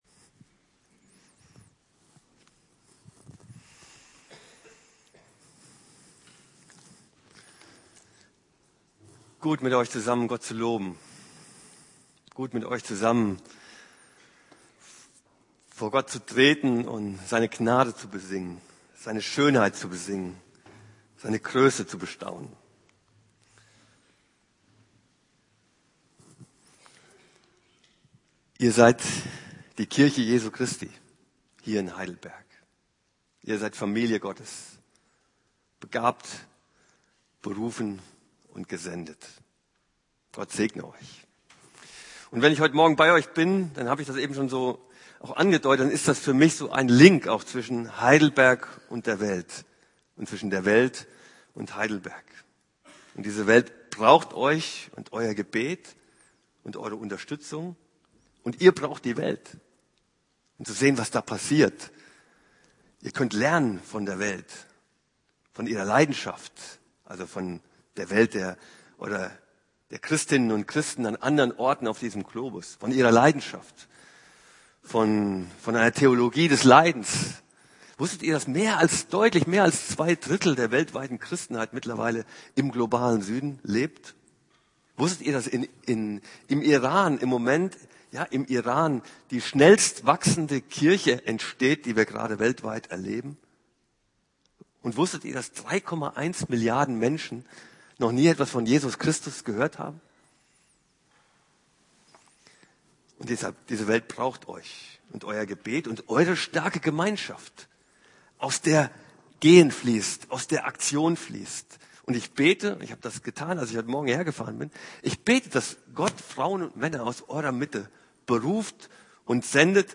Februar 2020 Predigt 1.Petrus